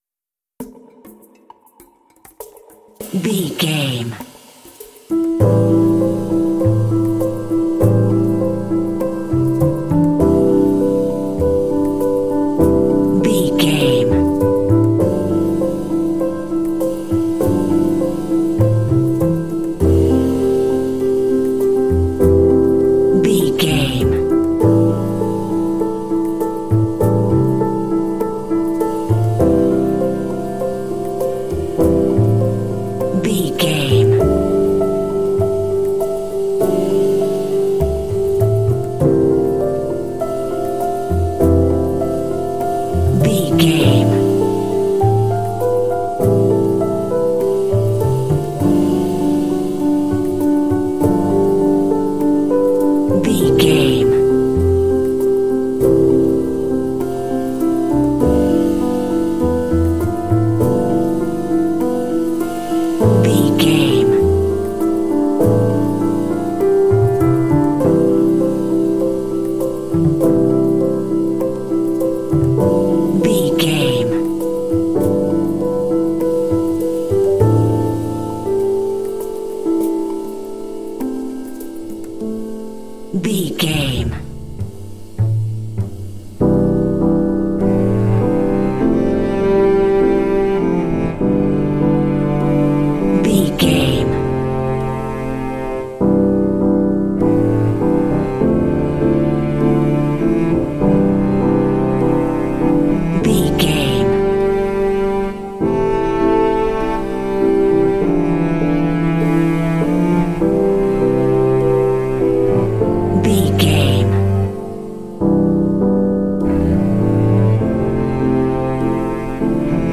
Aeolian/Minor
mellow
relaxed
calm
melancholy
mournful
piano
cello
percussion
modern jazz